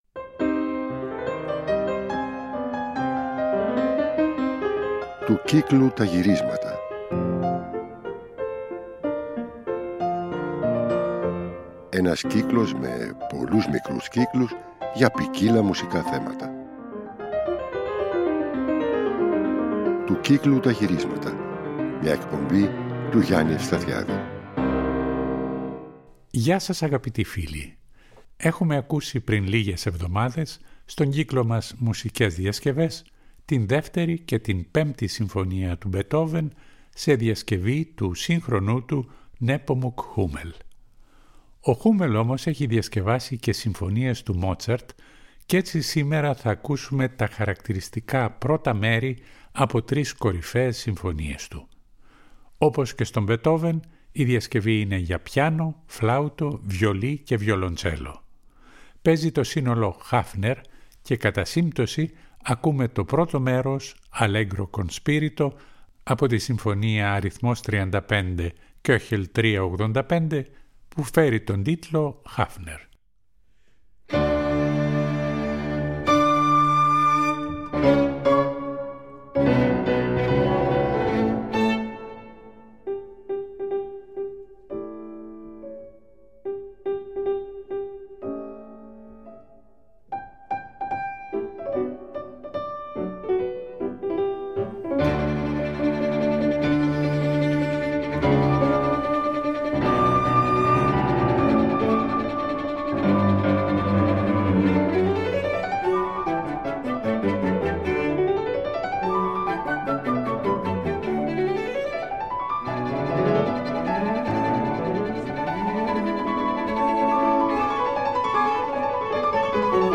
για πιάνο, βιολί και βιολοντσέλο
για μικρό σύνολο εγχόρδων
φωνητικού συγκροτήματος